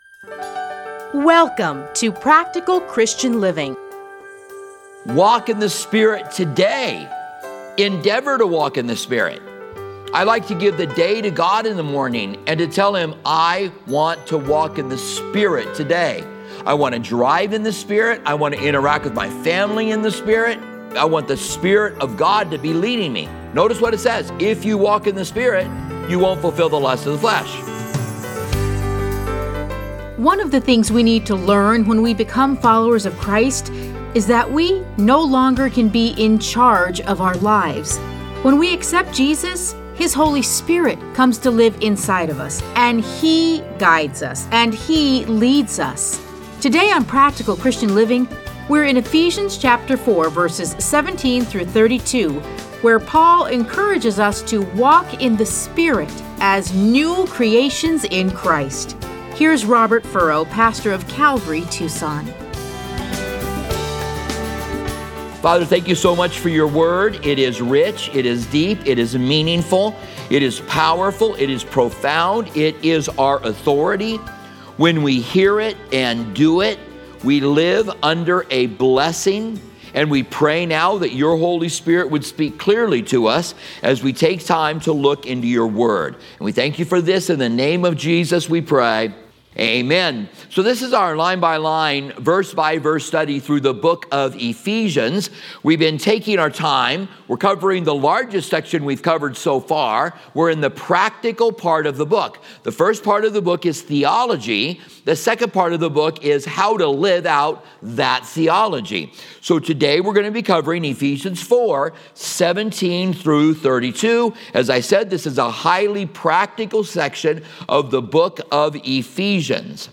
Listen to a teaching from Ephesians 4:17-32.